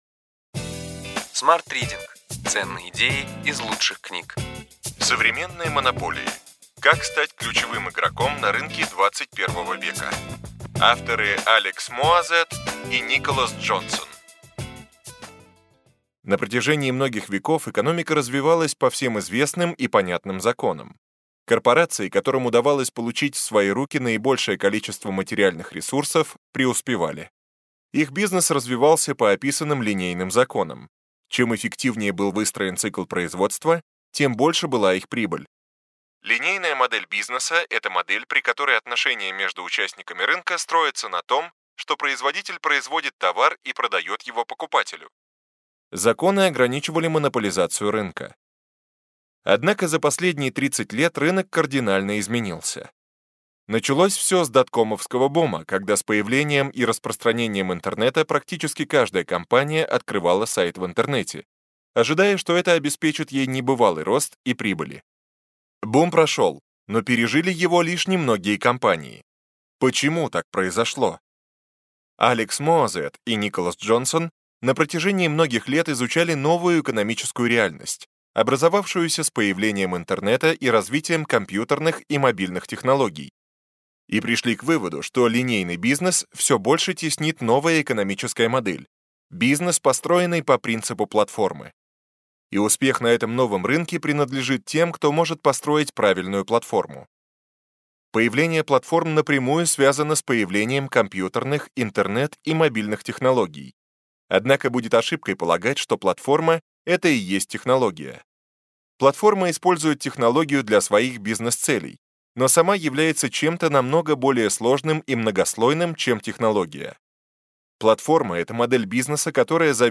Аудиокнига Ключевые идеи книги: Современные монополии: как стать ключевым игроком на рынке XXI века.